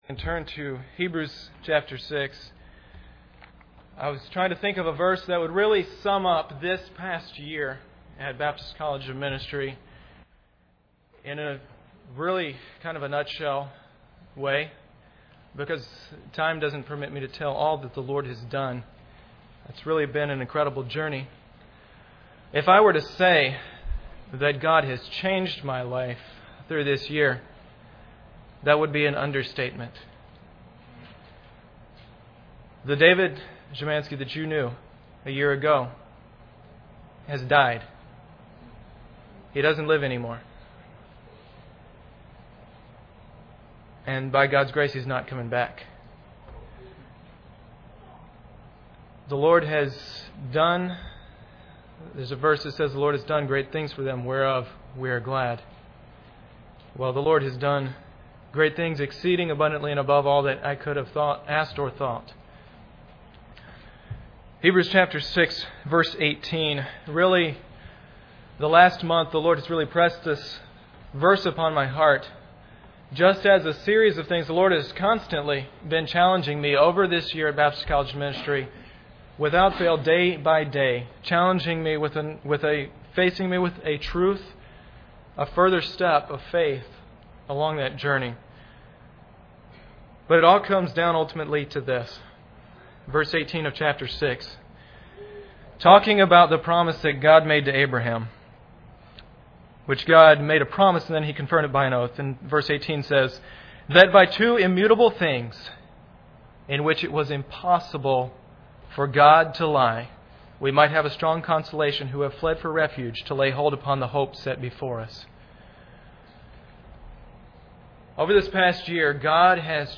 DR Team Testimony and Prayer Requests
Service Type: Sunday Evening